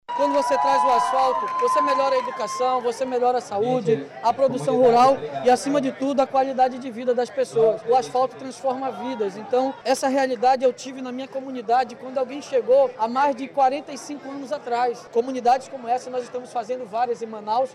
Durante a entrega do ramal Água Branca, o prefeito de Manaus, Davi Almeida, ressaltou as melhorias que as comunidades rurais alcançam com a chegada do asfalto.